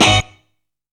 CHILDS HIT.wav